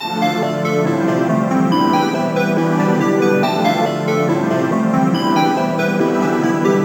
Gamer World Melody Loop 2.wav